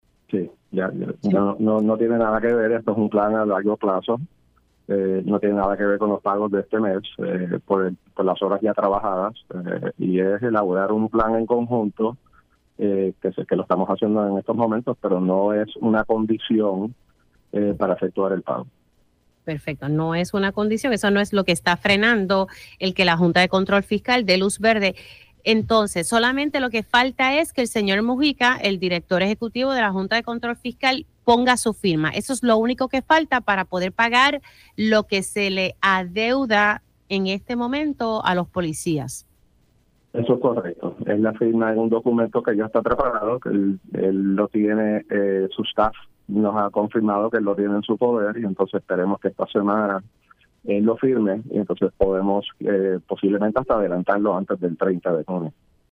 El secretario de Seguridad Pública, Arthur Garffer indicó en Pega’os en la Mañana que solamente falta la firma del director ejecutivo de la Junta de Supervisión Fiscal (JSF), Robert Mujica para poder aprobar los pagos de horas extra a los oficiales del Negociado de la Policía de Puerto Rico (NPPR).